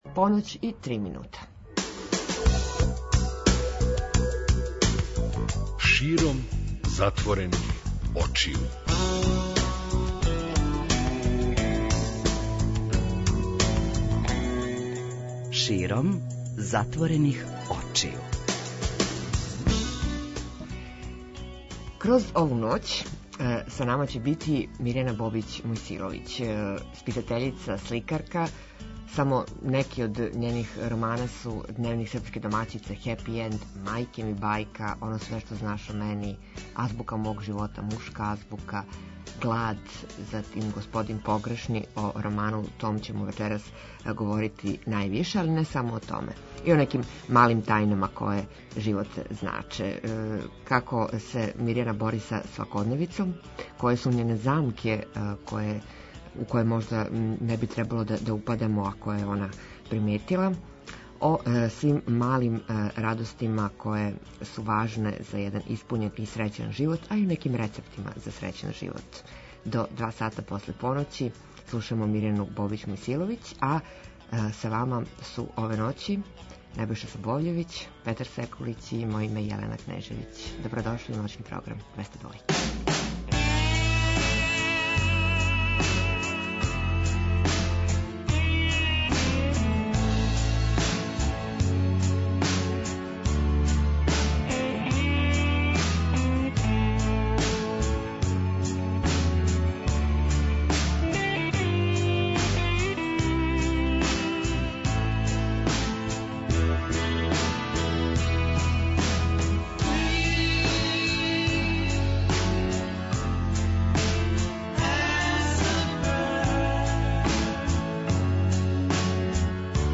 разговара